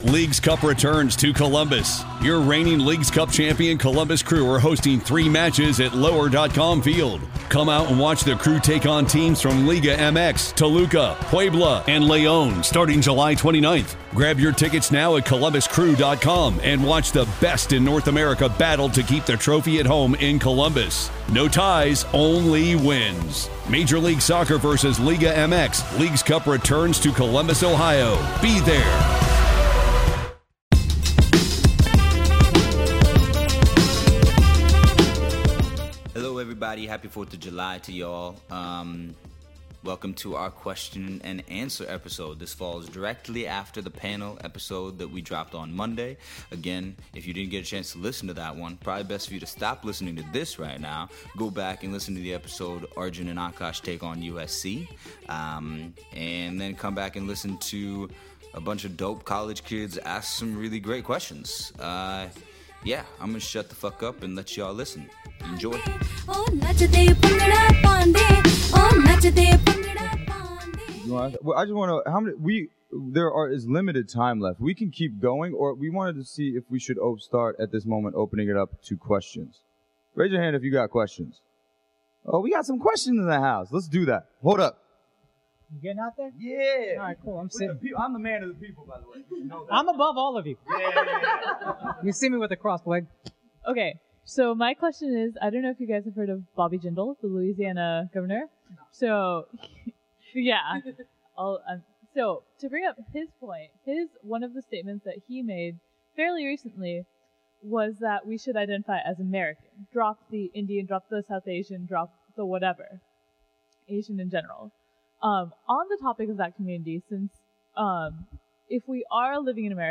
This is the Q&A section that immediately followed our panel event at USC. The conversation was lively and incredibly free flowing, but stayed within the themes touched on during the panel.